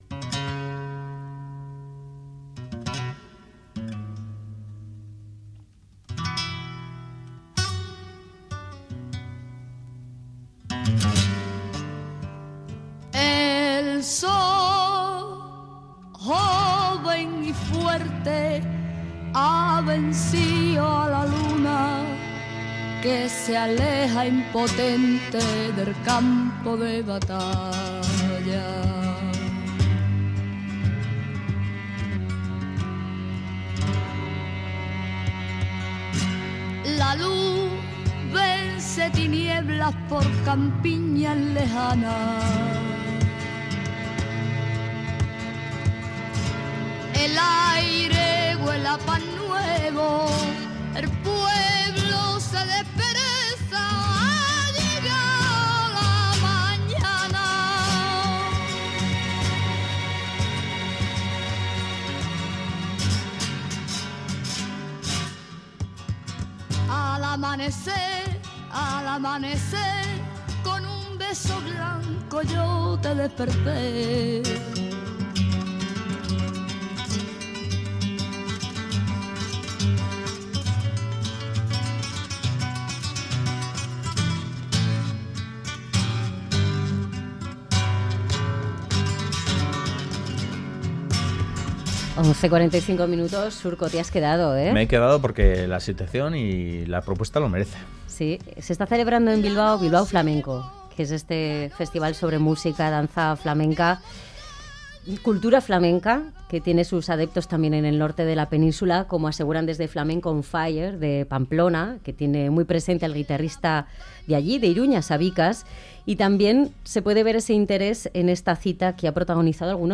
Lole Montoya, la mitad de Lole y Manuel, actúa hoy en Bilbao, pero antes se ha pasado por nuestro estudio para recordar a su difunto ex-marido y su trayectoria